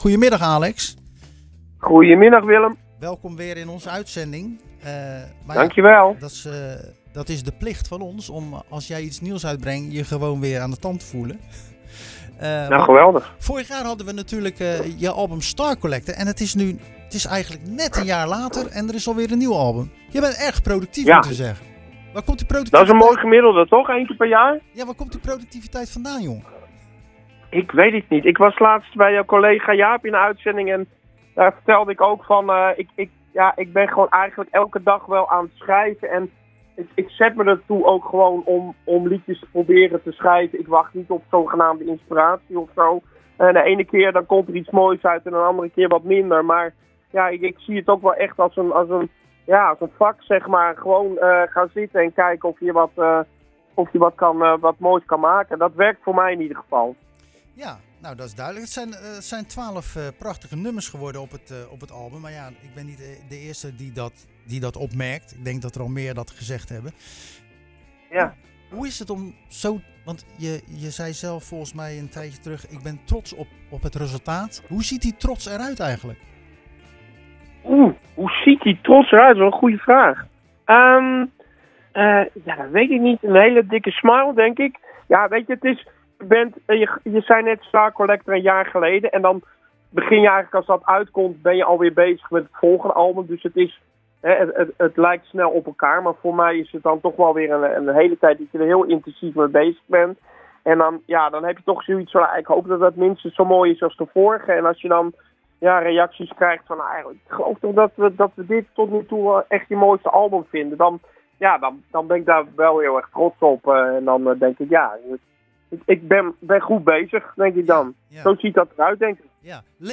We belde tijdens de wekelijkse editie van het programma